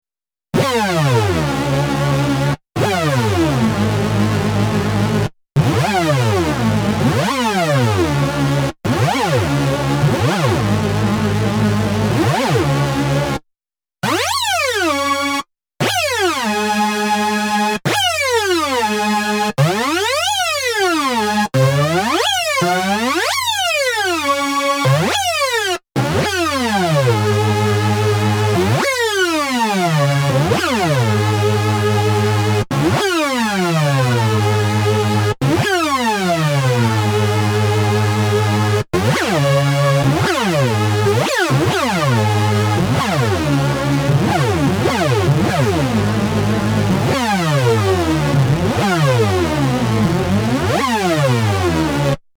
Challenge: anybody managed a half decent hoover?
This will give you the ingredients. (Sawtooths, PWM, pitch mod)
Spend 10 min watching that video and you’ll get to something like this.